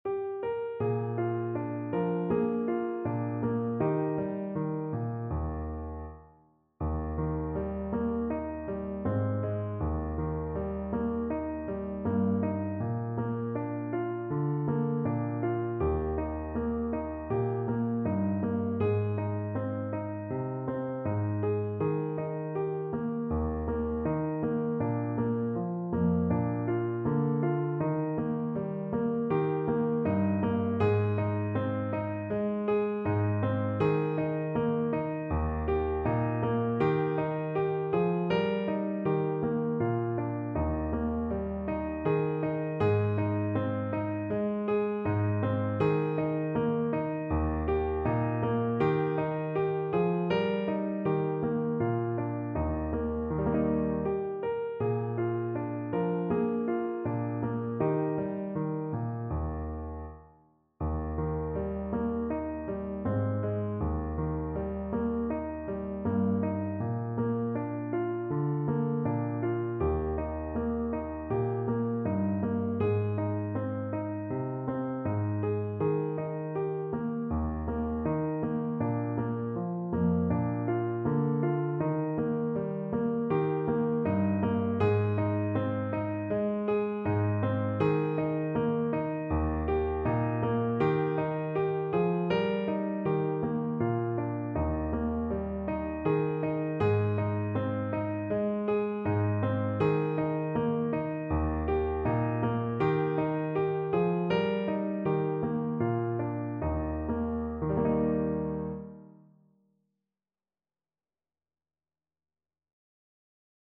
French Horn version
Play (or use space bar on your keyboard) Pause Music Playalong - Piano Accompaniment Playalong Band Accompaniment not yet available transpose reset tempo print settings full screen
French Horn
Traditional Music of unknown author.
4/4 (View more 4/4 Music)
Eb major (Sounding Pitch) Bb major (French Horn in F) (View more Eb major Music for French Horn )
Andante